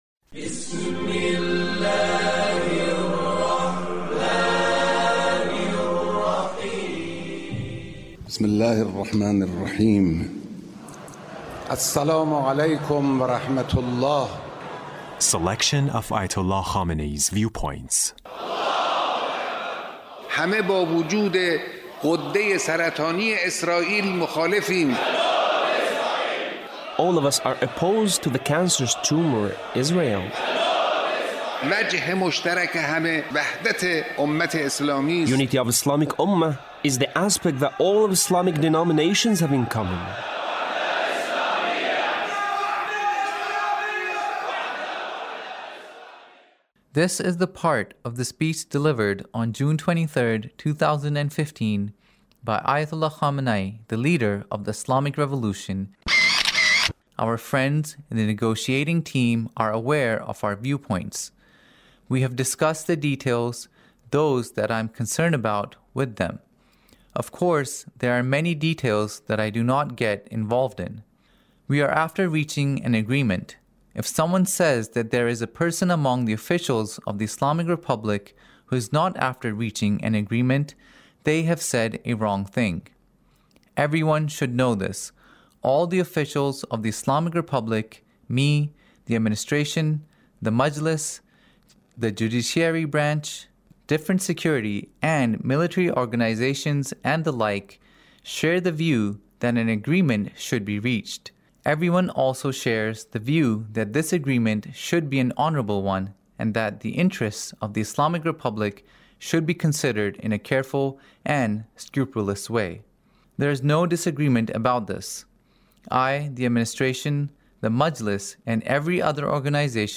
Leader's Speech in a Meeting with the Government Officials